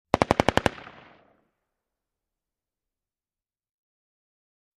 Rugar Mini 14 Machine Gun Burst From Distant Point of View, X5